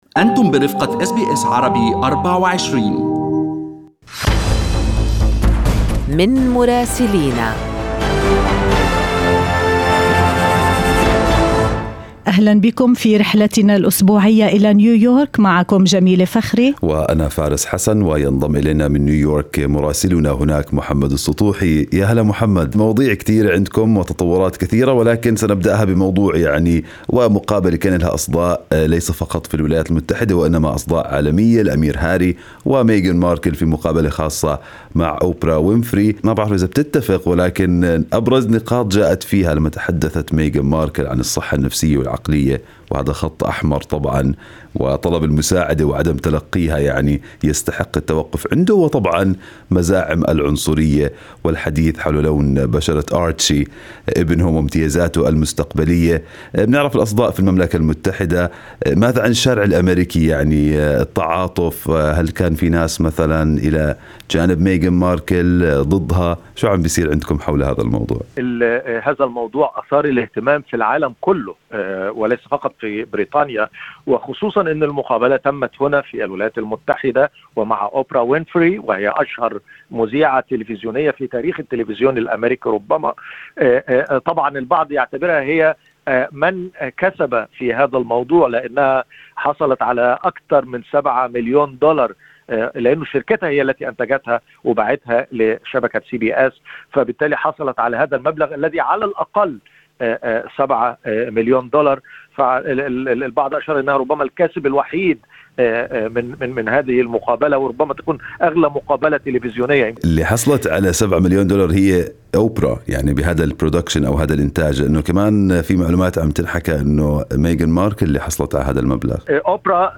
يمكنكم الاستماع إلى تقرير مراسلنا في نيويورك بالضغط على التسجيل الصوتي أعلاه.